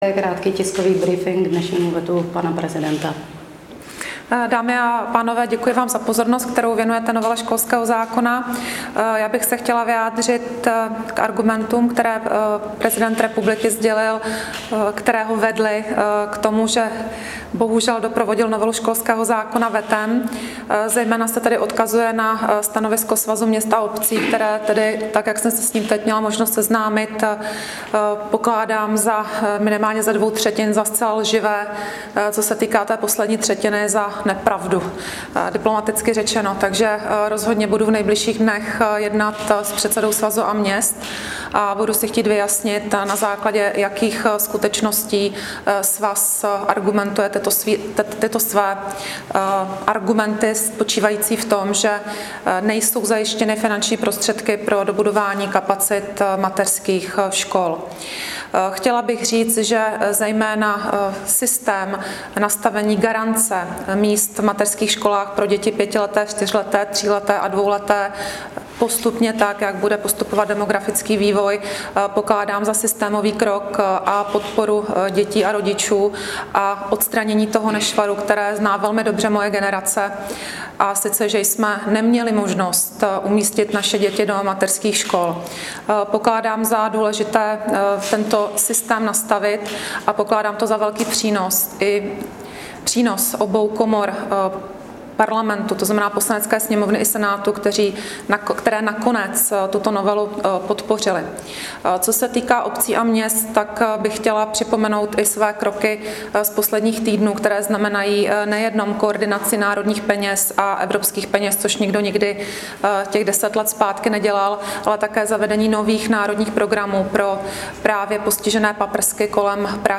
Vyjádření ministryně Valachové z brífinku 5. května lze přehrát zde: